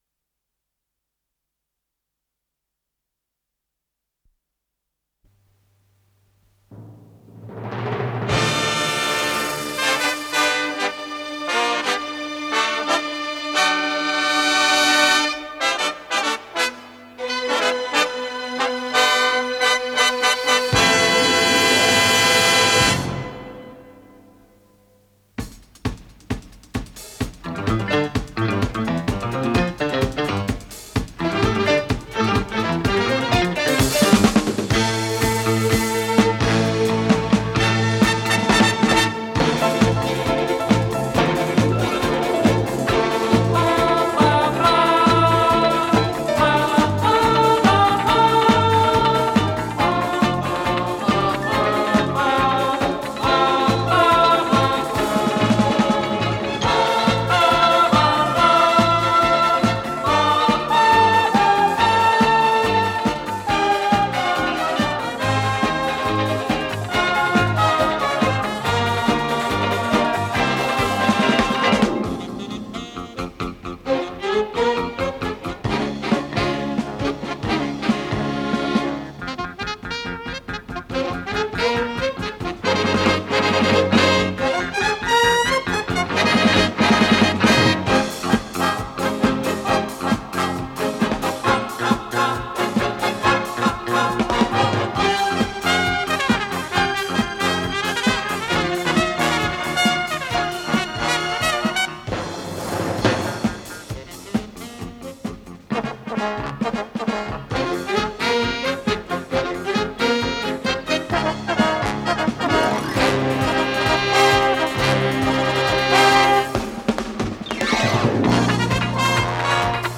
с профессиональной магнитной ленты
Вокальный ансамбль
ВариантДубль моно